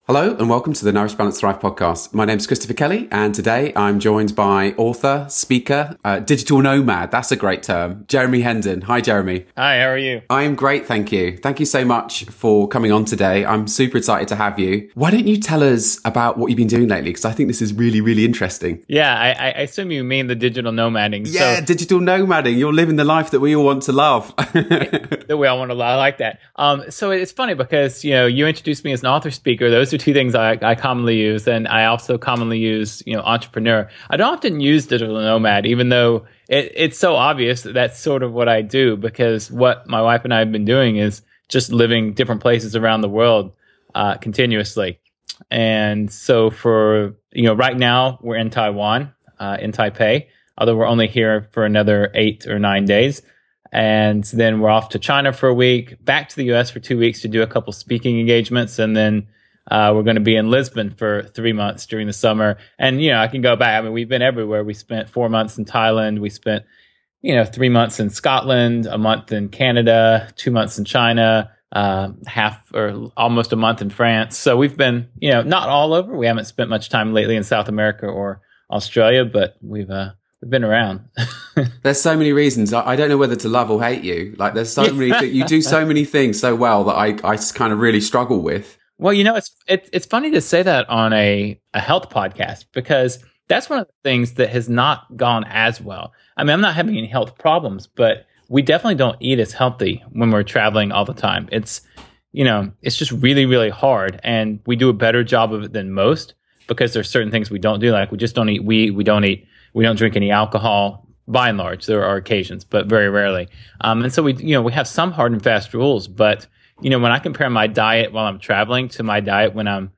Here’s the outline of this interview